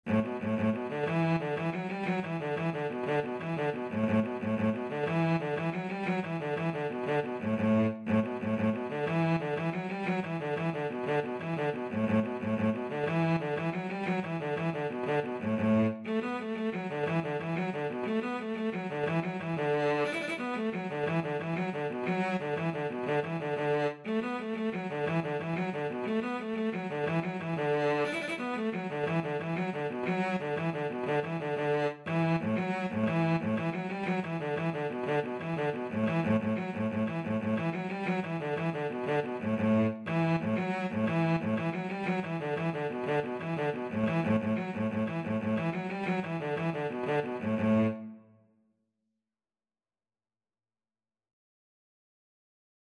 Cello version
6/8 (View more 6/8 Music)
A3-D5
Cello  (View more Intermediate Cello Music)
Traditional (View more Traditional Cello Music)